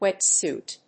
/ˈwɛˌtsut(米国英語), ˈweˌtsu:t(英国英語)/
アクセントwét sùit